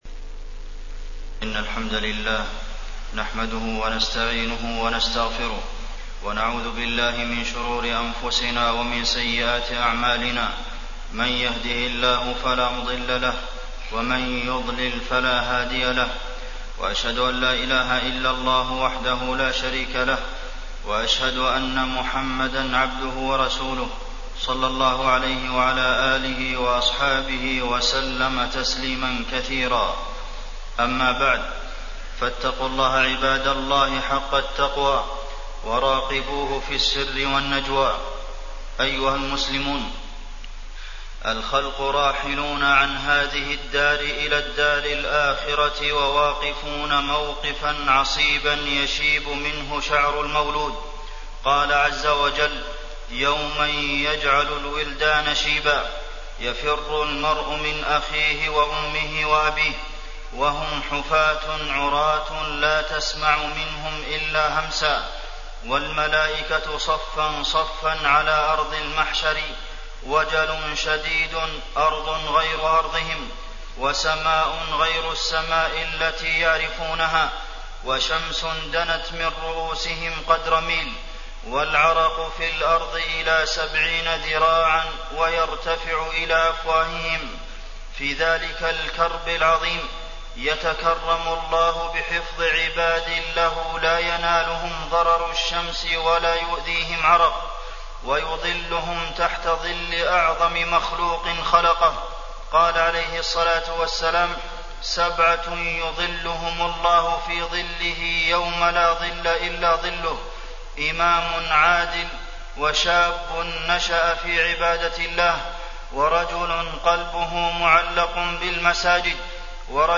تاريخ النشر ١٦ شعبان ١٤٣٠ هـ المكان: المسجد النبوي الشيخ: فضيلة الشيخ د. عبدالمحسن بن محمد القاسم فضيلة الشيخ د. عبدالمحسن بن محمد القاسم الإخلاص في العبادة